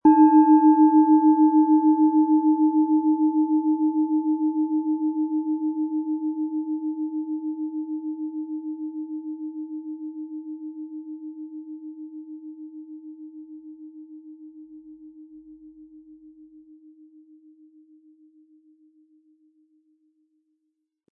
Planetenton 1
Planetenschale® Sinnlich Sein und Fühlen & Hemmungen verlieren mit Eros, Ø 13,4 cm inkl. Klöppel
Im Sound-Player - Jetzt reinhören können Sie den Original-Ton genau dieser Schale anhören.
Der richtige Schlegel ist umsonst dabei, er lässt die Klangschale voll und angenehm erklingen.
SchalenformBihar
MaterialBronze